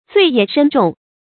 罪业深重 zuì yè shēn zhòng 成语解释 指做了很大的坏事，犯了很大的罪。